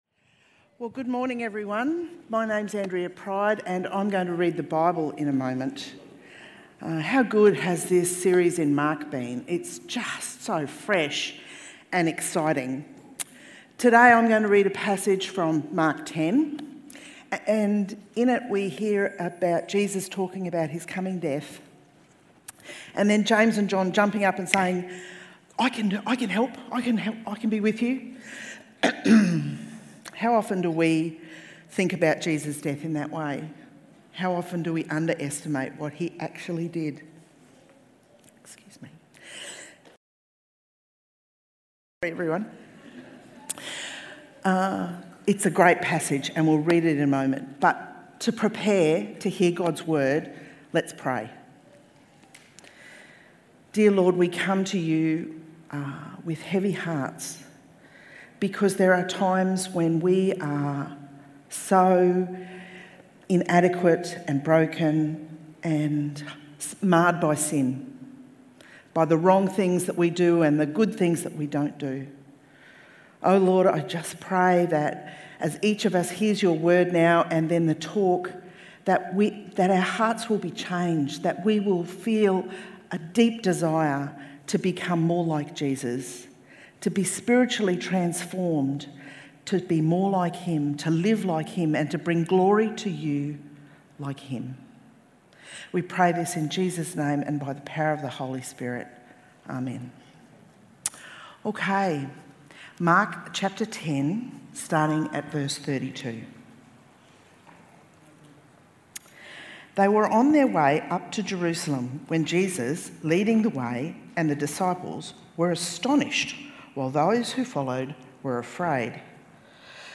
ServantKingReadingAndTalk.mp3